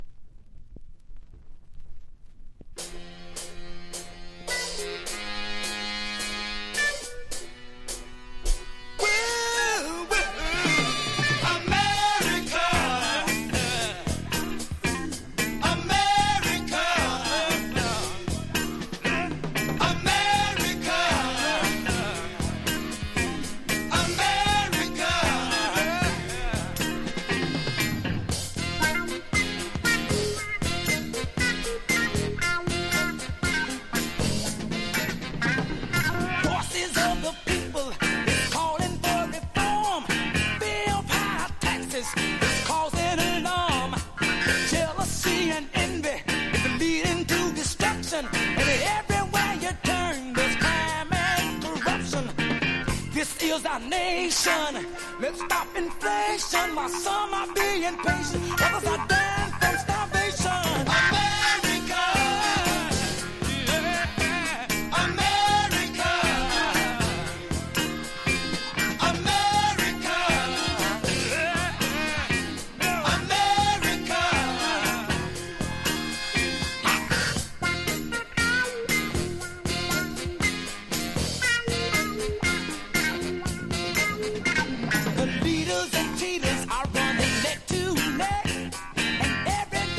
FUNK / DEEP FUNK